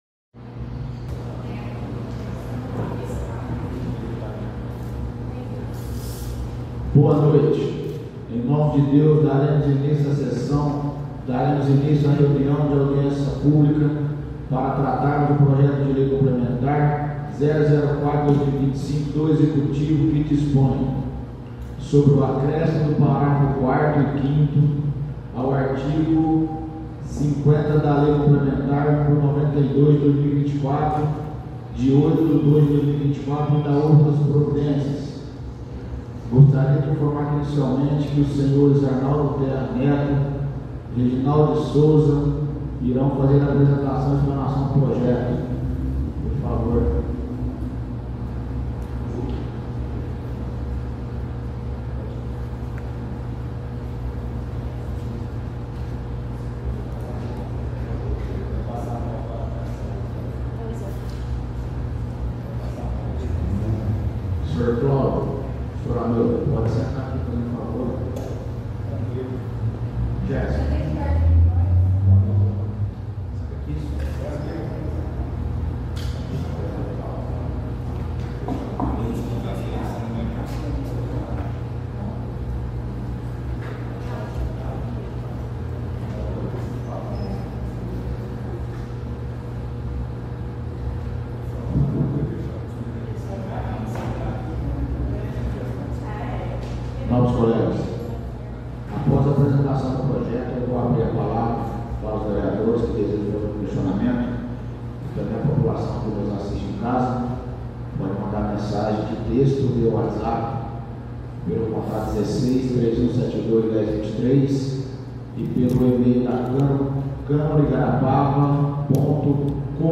Áudio - Audiência Pública - 01/04/2025